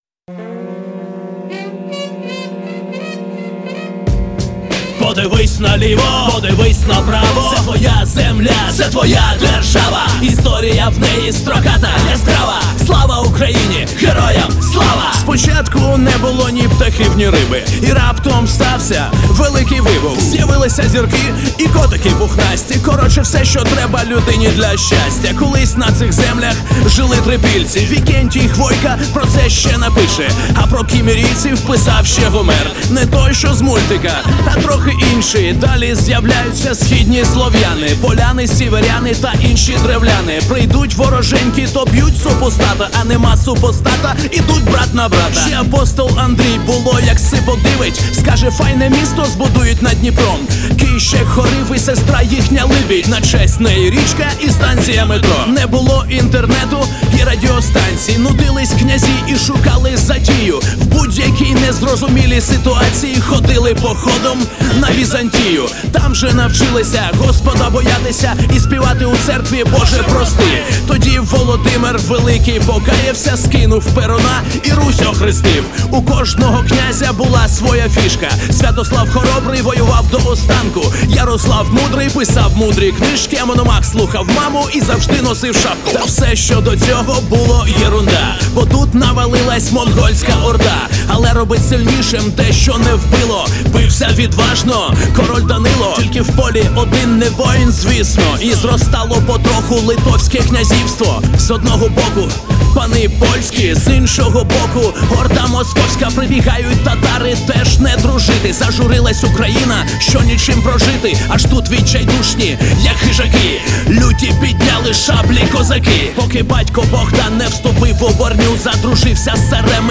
Всі мінусовки жанру Hip-Hop
Плюсовий запис